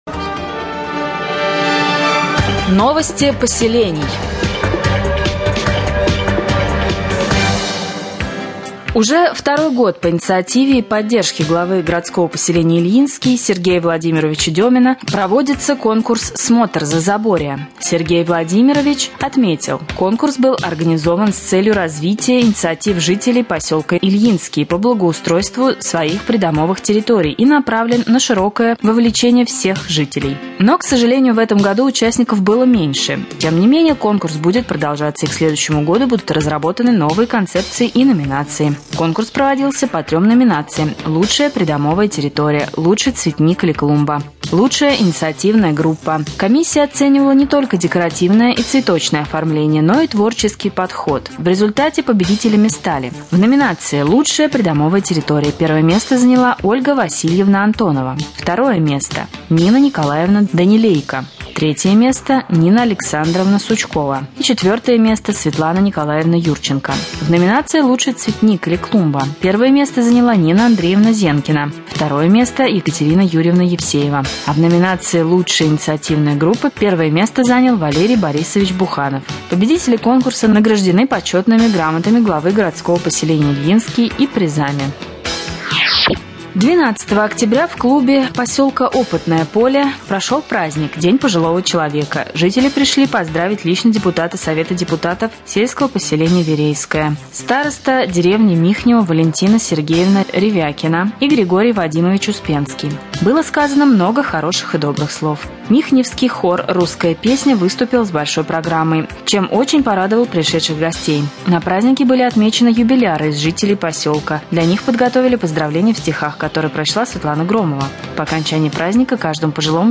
1.Новости4.mp3